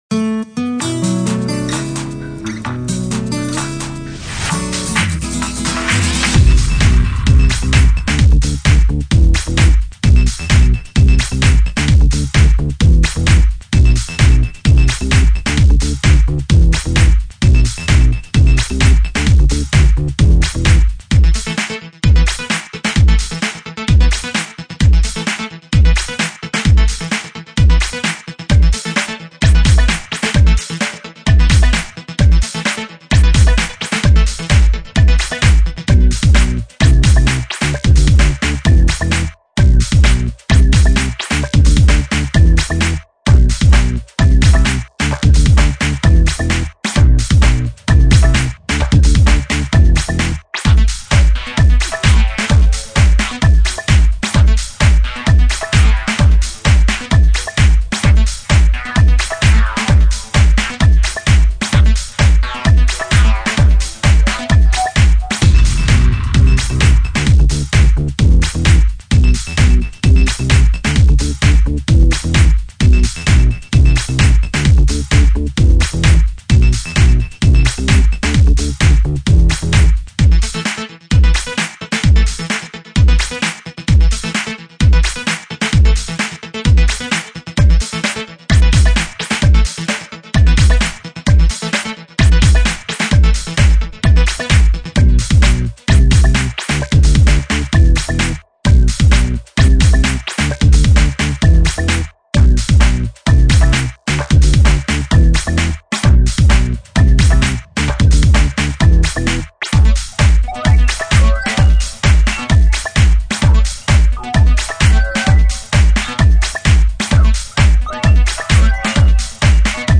Leuk, maar niet 'warm' genoeg